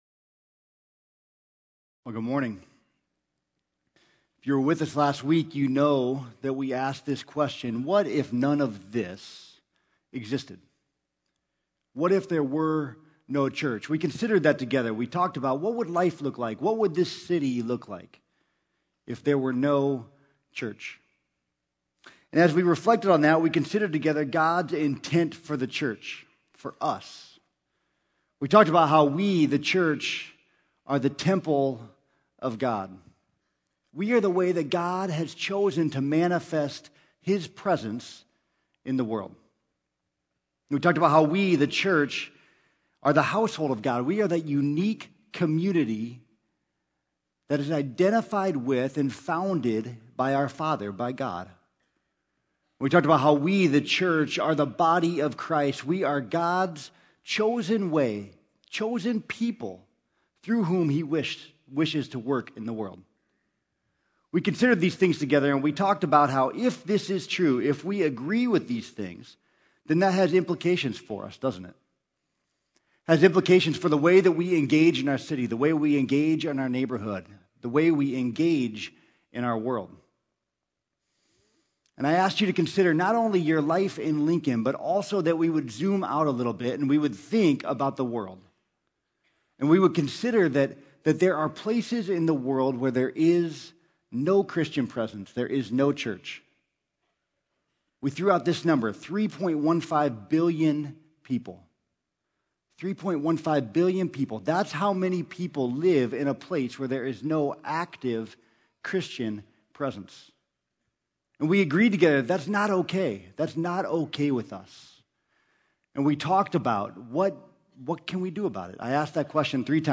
Sermon: What Are We Going to Do About it?